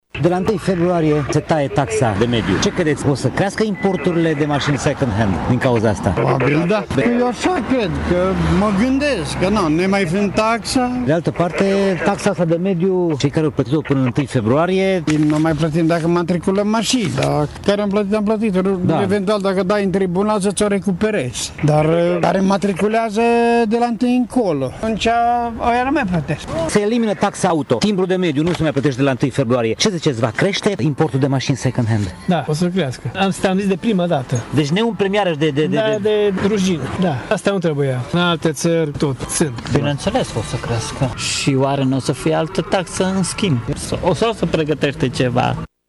Taxa ar fi trebuit să rămână, sunt de părere șoferii târgumureșeni. Aceștia cred că țara se va umple din nou de mașini vechi și că actualii guvernanți pregătesc între timp o altă taxă: